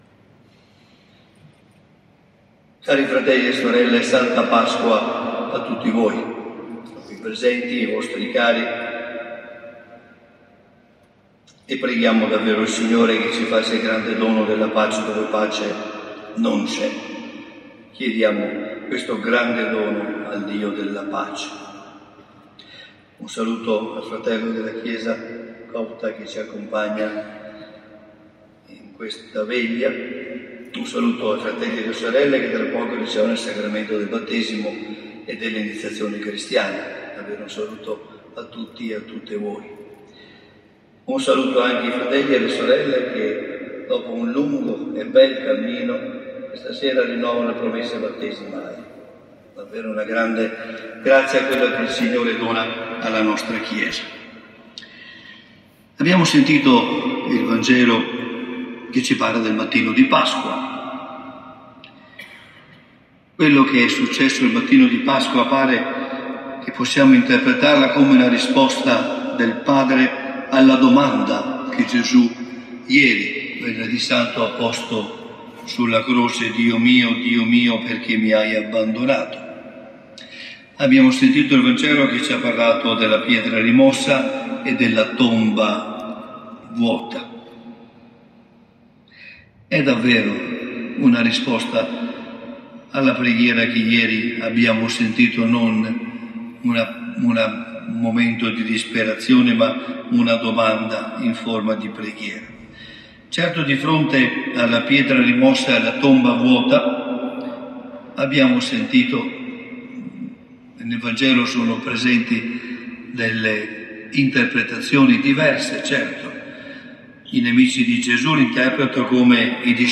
Nella serata di sabato 30 marzo l’Arcivescovo ha presieduto la Veglia Pasquale in Cattedrale, iniziata con il rito del fuoco sul sagrato di San Lorenzo.